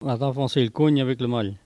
Maraîchin
Catégorie Locution